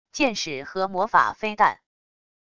箭矢和魔法飞弹wav音频